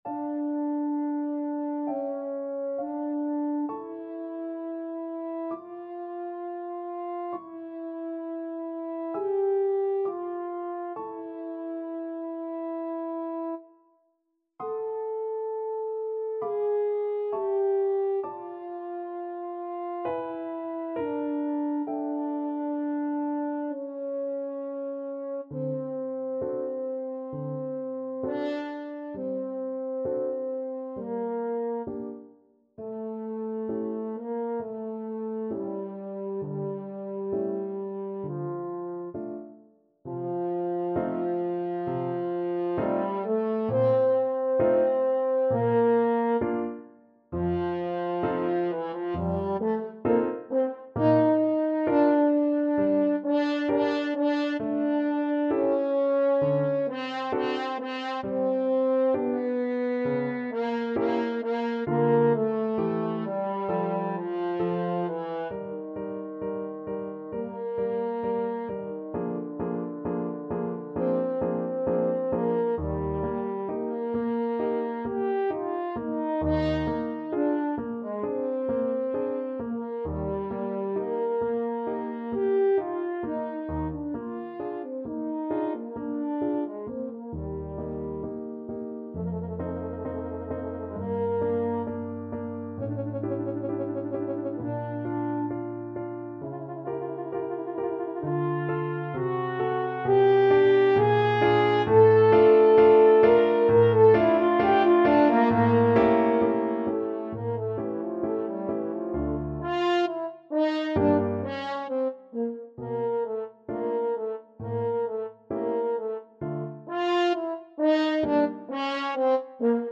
French Horn
D minor (Sounding Pitch) A minor (French Horn in F) (View more D minor Music for French Horn )
Andante =66
Classical (View more Classical French Horn Music)
traviata_act3prelude_HN.mp3